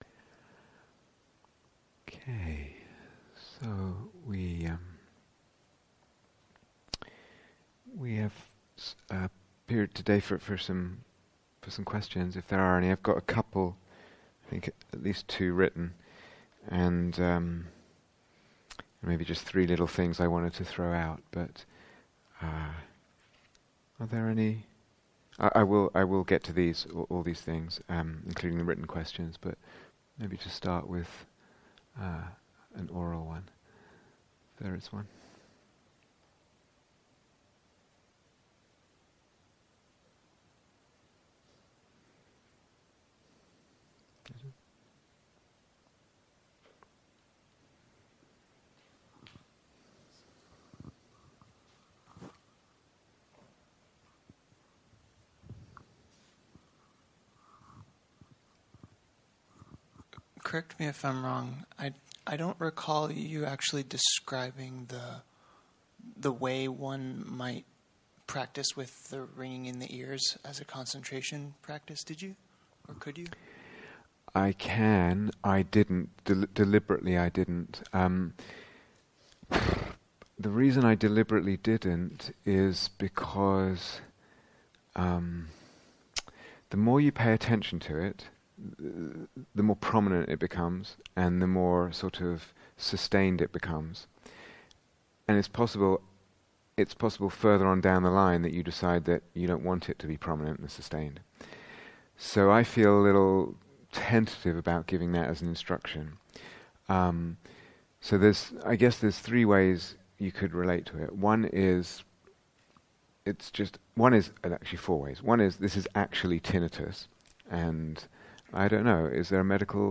Q & A